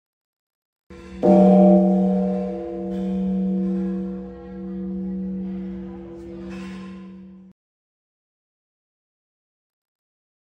Tiếng Chuông Chùa Ngắn, 1 tiếng duy nhất
Thể loại: Tiếng chuông, còi
Description: Tiếng chuông chùa ngắn, vang lên một tiếng duy nhất, ngân dài và lan tỏa trong không gian tĩnh lặng, tạo cảm giác bình yên và linh thiêng. Âm thanh vọng xa, quen thuộc với đời sống tâm linh tại Việt Nam, thường vang lên từ những ngôi chùa cổ kính vào mỗi sớm mai hoặc chiều tà. Hiệu ứng âm thanh tạo không khí trầm mặc, tĩnh tại và gợi nhớ về không gian chùa chiền thanh tịnh.
tieng-chuong-chua-ngan-1-tieng-duy-nhat-www_tiengdong_com.mp3